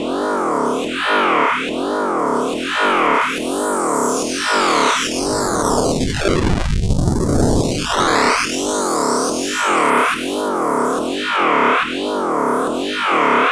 FX 142-BPM.wav